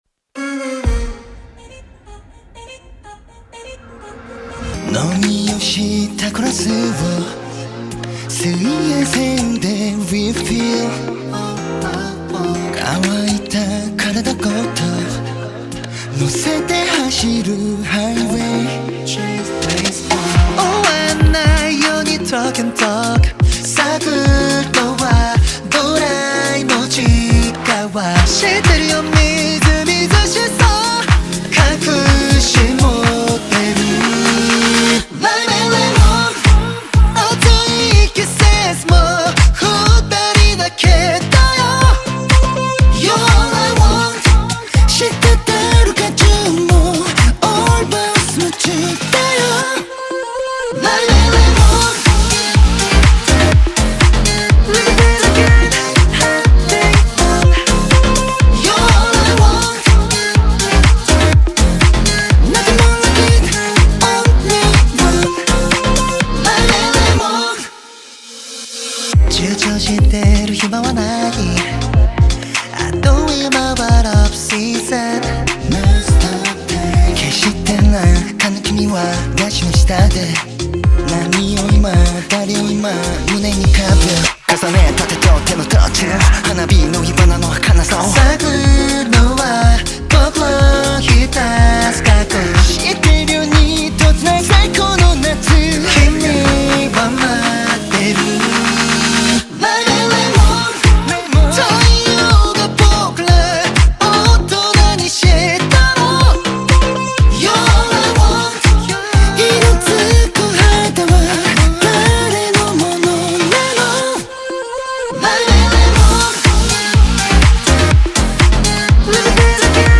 Hip Hop
South Korean male pop duo